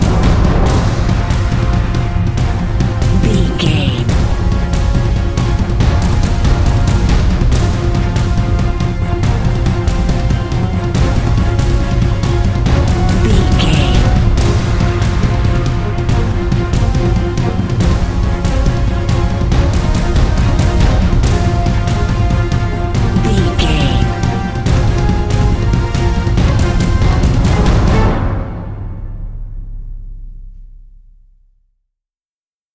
Epic / Action
Fast paced
In-crescendo
Aeolian/Minor
A♭
Fast
strings
drums
orchestral hybrid
dubstep
aggressive
energetic
intense
bass
synth effects
wobbles
driving drum beat
epic